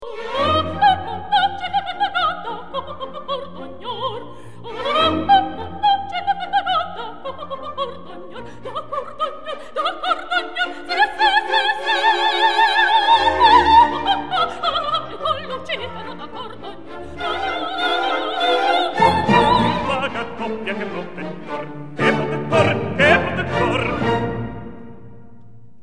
Ballata: